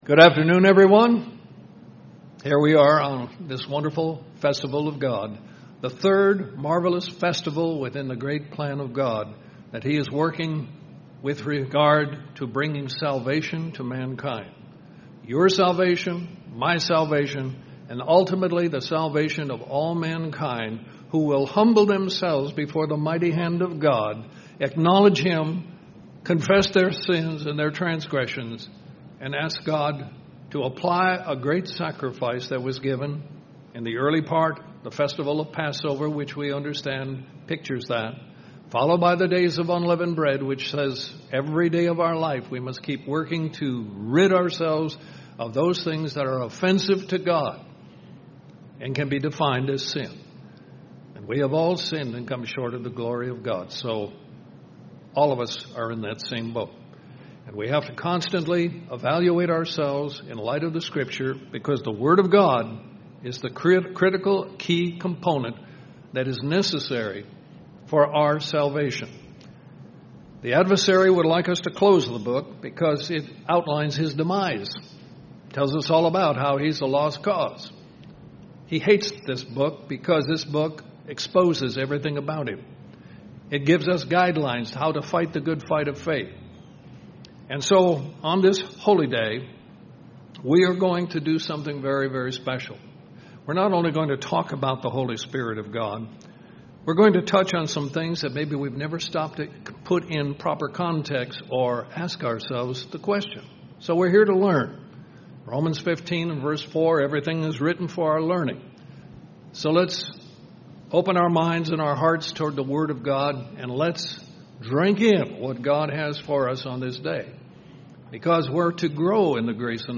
Are you accessing God's spirit in your daily life? This sermon takes a look at the different ways God's spirit works in your life.